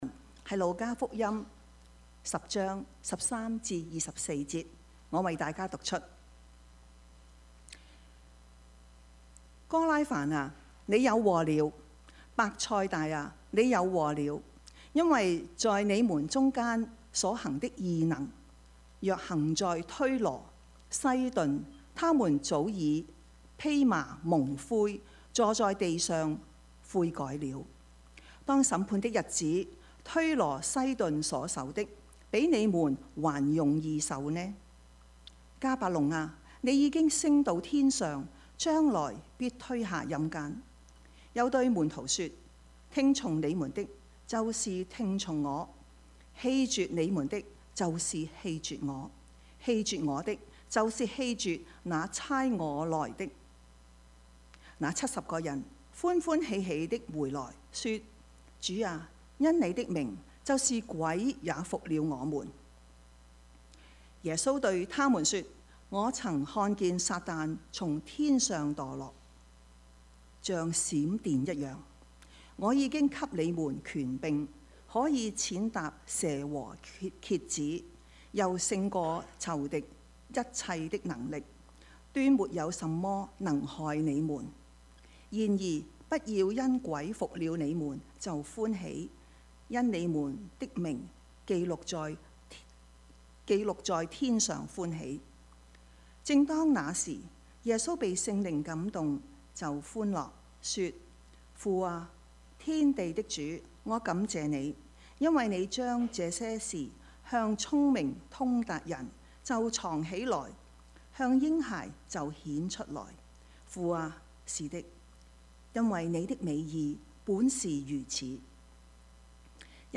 Service Type: 主日崇拜
Topics: 主日證道 « 機不可失 人間有情 »